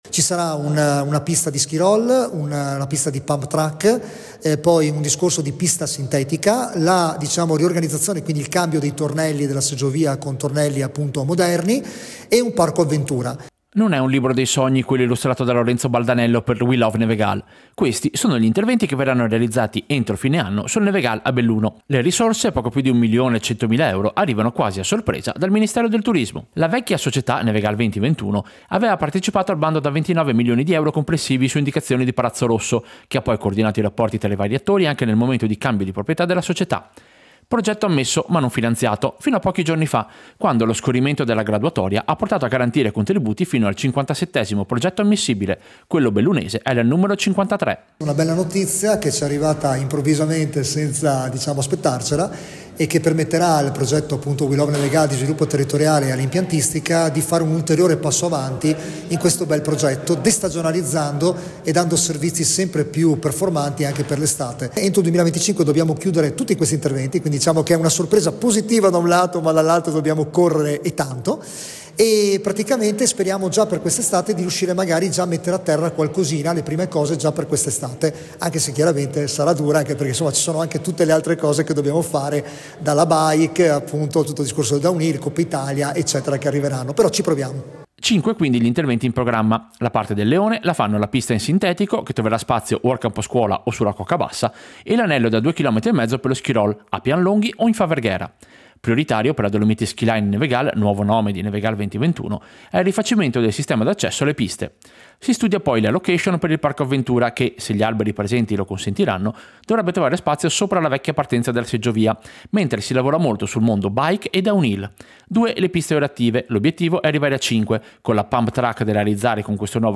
Servizio-Contributo-destagionalizzazione-Nevegal.mp3